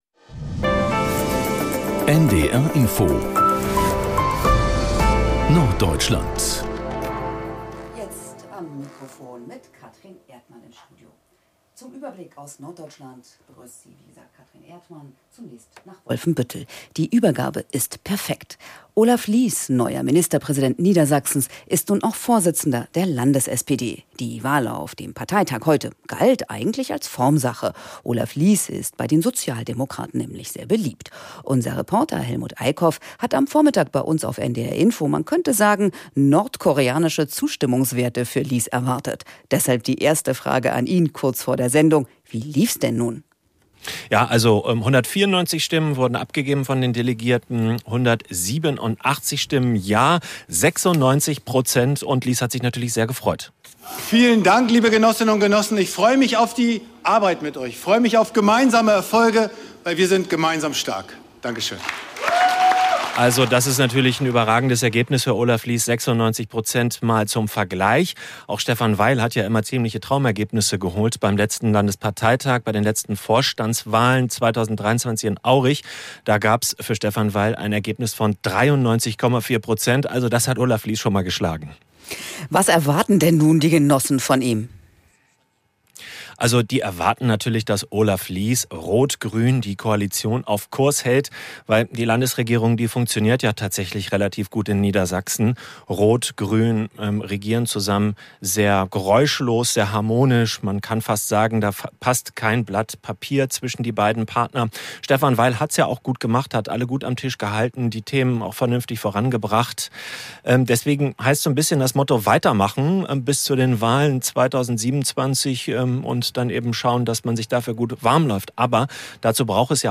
… continue reading 532 つのエピソード # Nachrichten # NDR Info # Tägliche Nachrichten